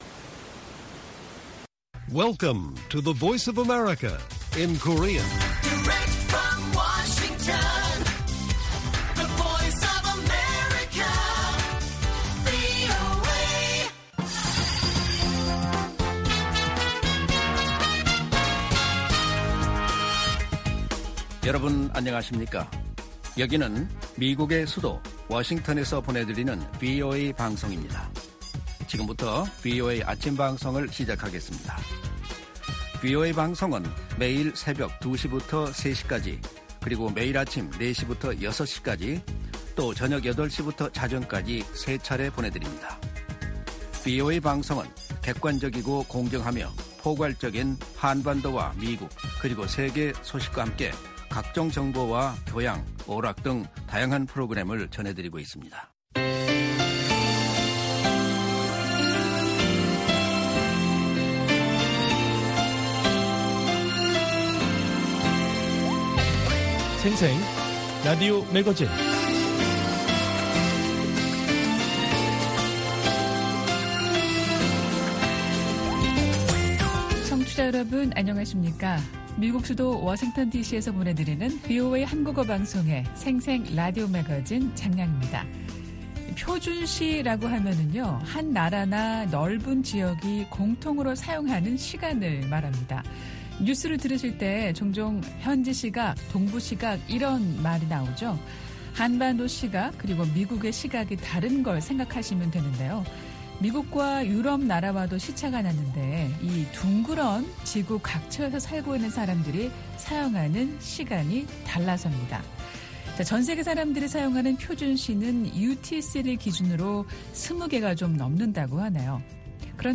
VOA 한국어 방송의 월요일 오전 프로그램 1부입니다.